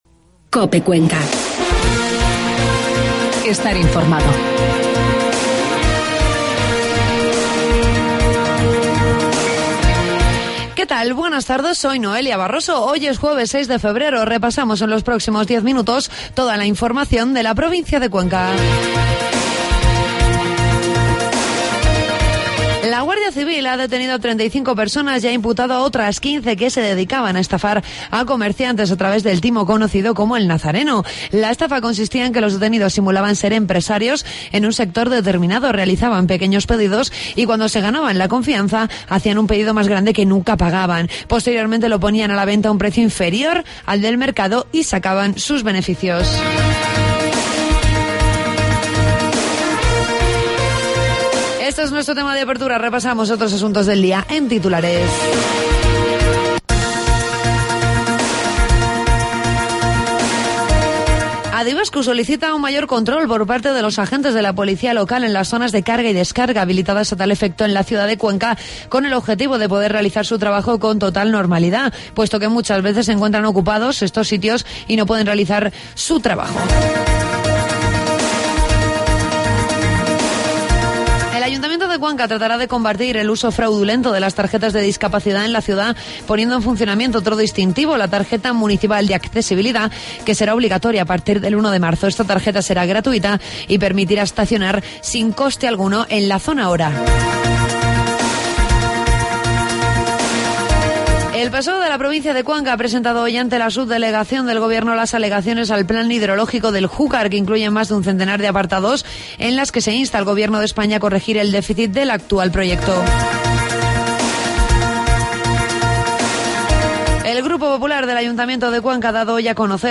Toda la información de la provincia de Cuenca en los informativos de mediodía de COPE